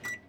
Microwave Beep Sound
household